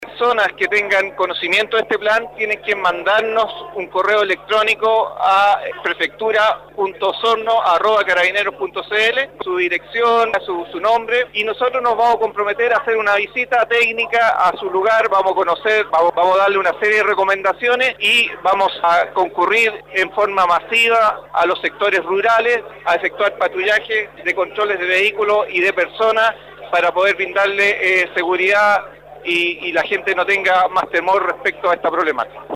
Al respecto, el prefecto de Carabineros de Osorno, Coronel Leonardo Castillo detalló que el tema de la seguridad hay que compartirlo y que no solamente tiene que estar focalizado en las policías y las fiscalías, sino que también tiene que participar la comunidad. El prefecto de Carabineros de Osorno en conversación con radio Sago, se refirió al “Plan Volcán Osorno” para disminuir la ola de asaltos violentos en sectores rurales.